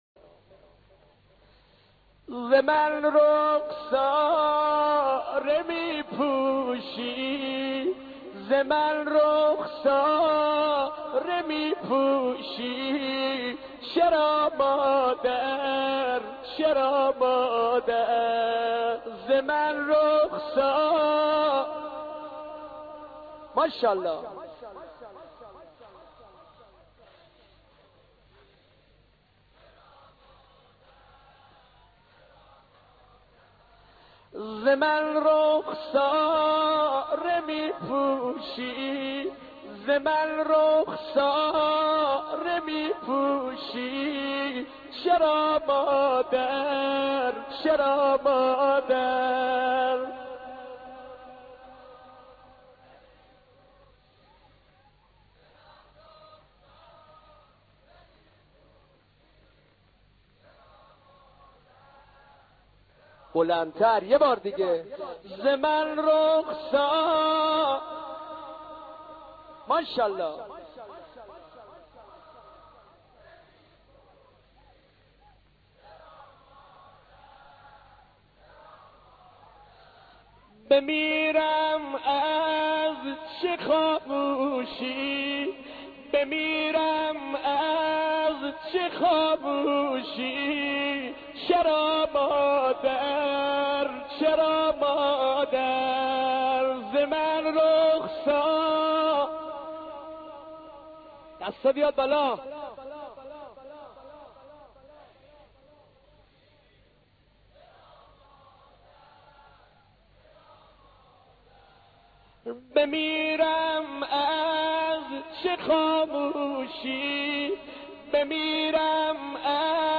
نوحه شهادت حضرت فاطمه